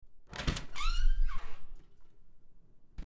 Door Opening
Door_opening.mp3